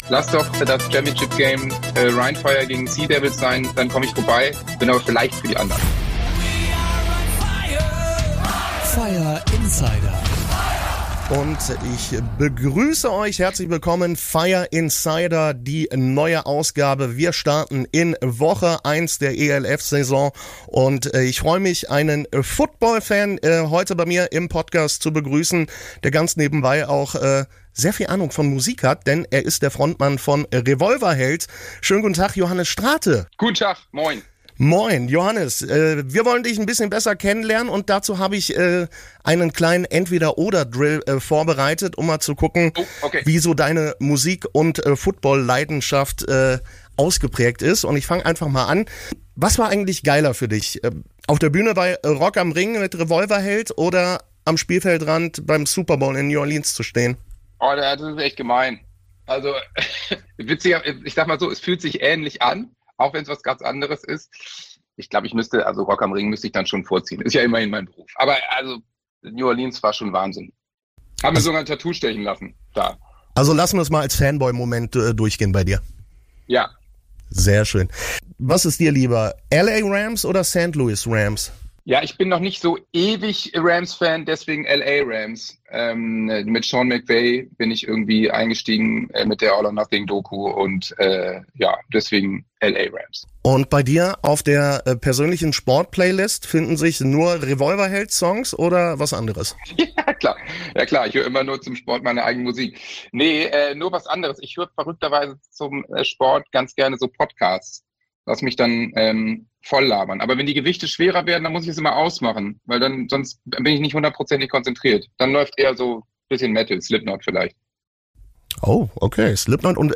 Beschreibung vor 11 Monaten Wir begrüßen Revolverheld-Frontmann Johannes Strate in der neuen Ausgabe von Fire Insider. Im Talk